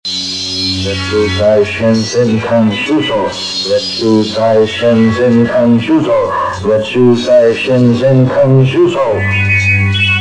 ニンジャ映画の印象的な台詞をwavやmp3形式にしてみました。